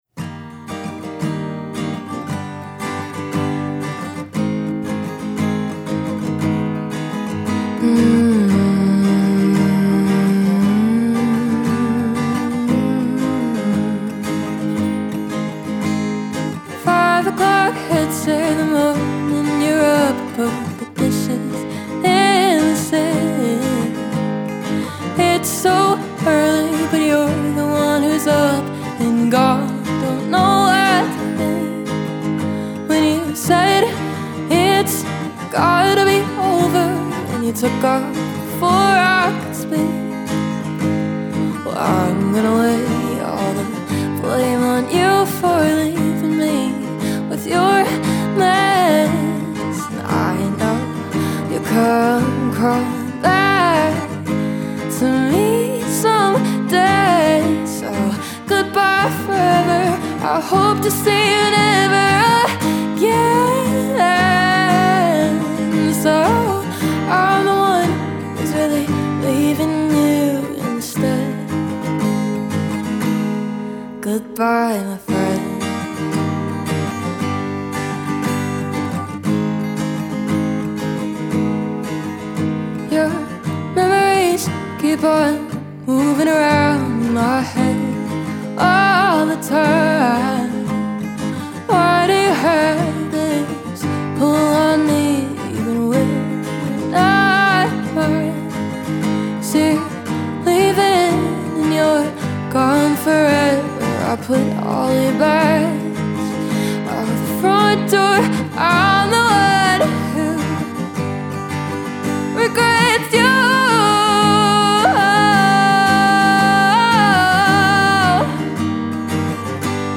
At Tank Recording Studio